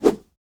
Stick-Swoosh Whoosh